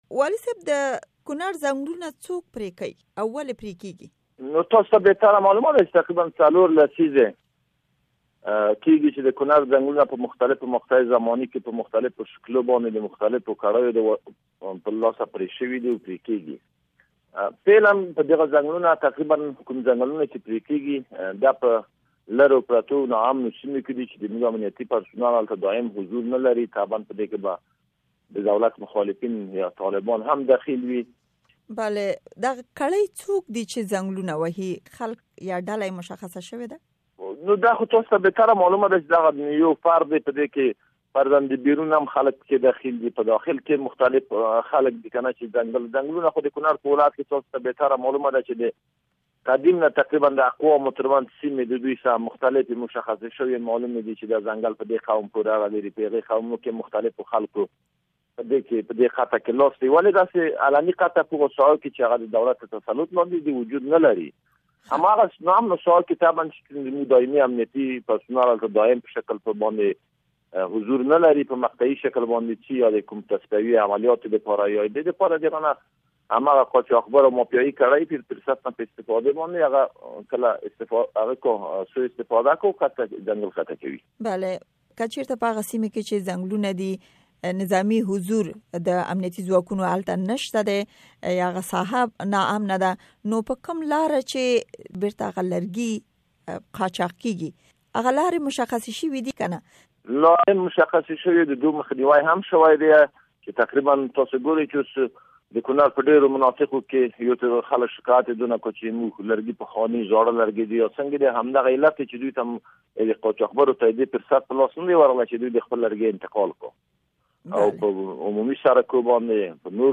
د کونړ والي وحيدالله کليمزي مرکه دلته واورئ